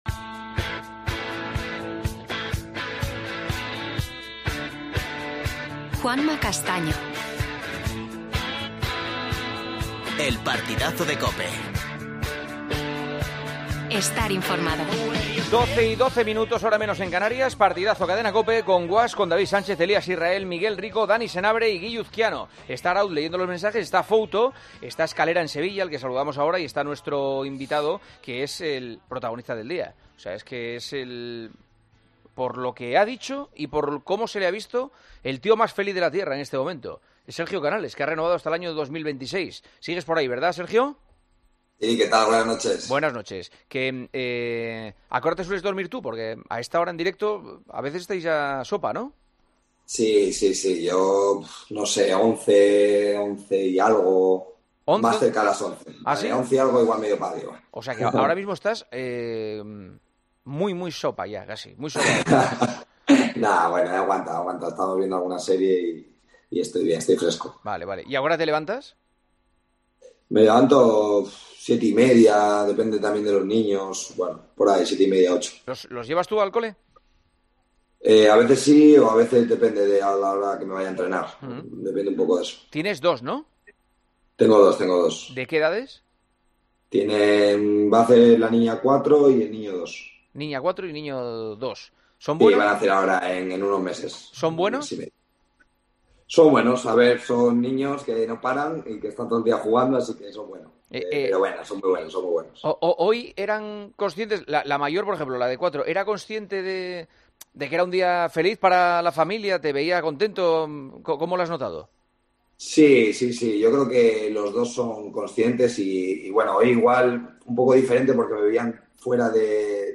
AUDIO: Entrevista en directo al jugador del Betis Sergio Canales.